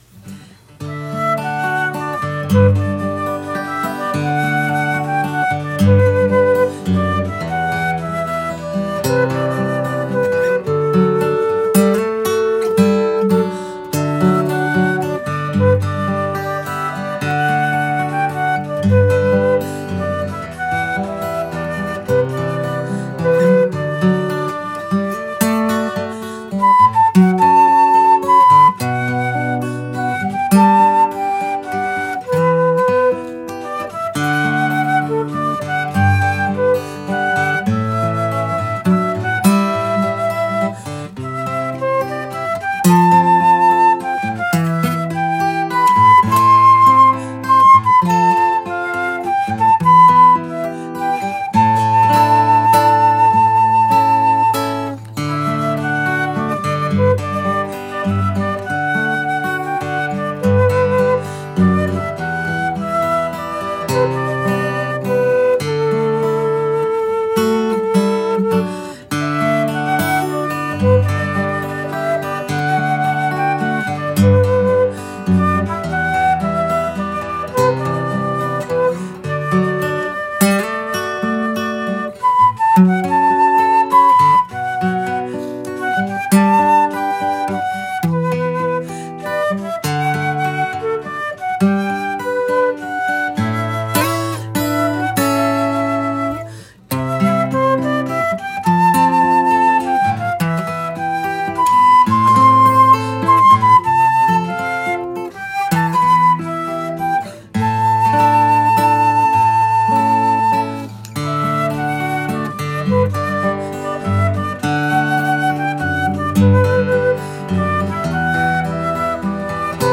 Today's recording is one of the few that features "guest artists."
guitar
playing the flute. The tune is beautifully played and I'm happy to share it here.